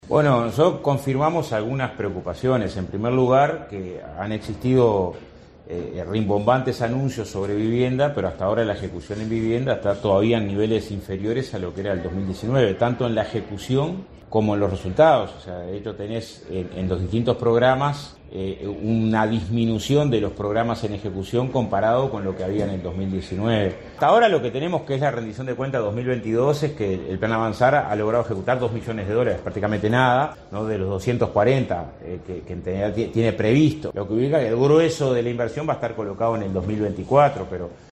Declaraciones del senador del FA, Óscar Andrade: